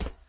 scpcb-godot/SFX/Step/SCP/StepSCP2.ogg at 423912bbded30835f02a319640a5813ecd8cd6ca
StepSCP2.ogg